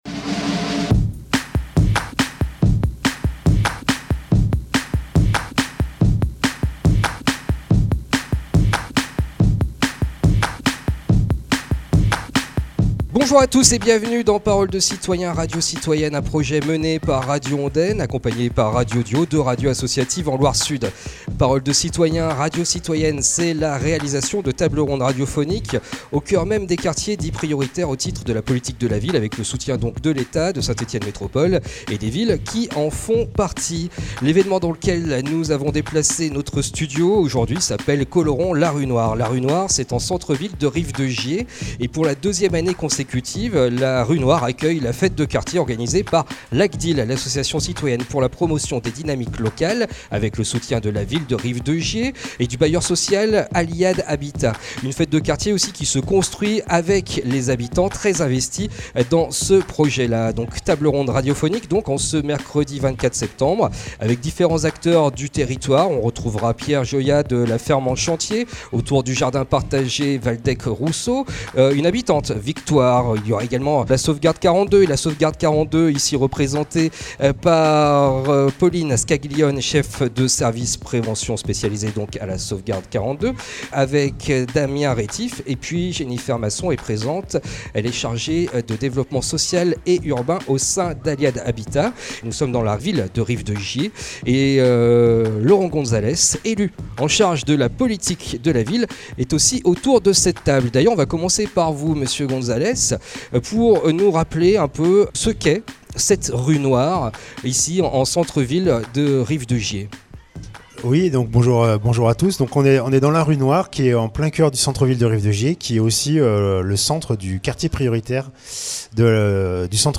Paroles de Citoyens, Radio Citoyennes est un projet mené dans les quartiers prioritaires au titre du contrat de ville, qui délocalise les studios de Radio Ondaine et Radio Dio, 90.9 fm et 89.5, au cœur de ces mêmes quartiers de Saint-Etienne Métropole, pour éclairer le grand public sur les initiatives conduites autour des piliers de Quartiers 2030, soit, le développement économique et l’emploi, le cadre de vie et le renouvellement urbain, et bien sûr la cohésion sociale.
C’est atour de ce dernier point que nous nous sommes rendus, mercredi 24 Septembre, en centre ville de Rive de Gier pour l’après-midi, Colorons la Rue Noire.